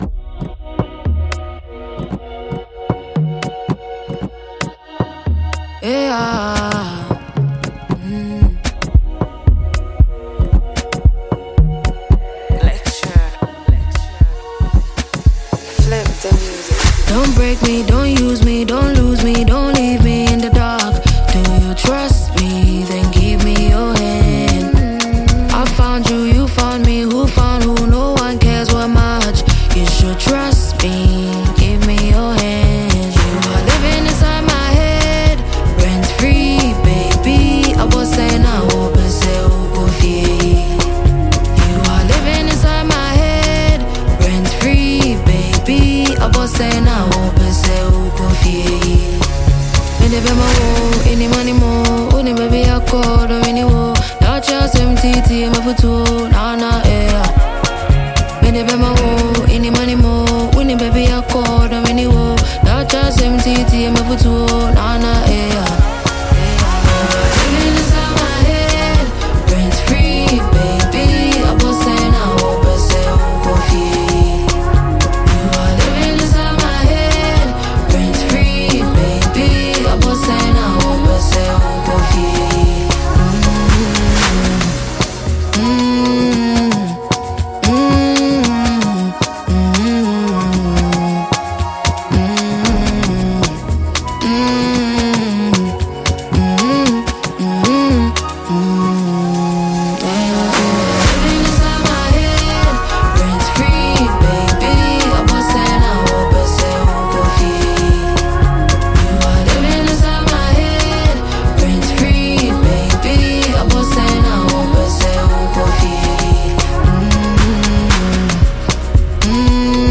R&B and afro-fusion singer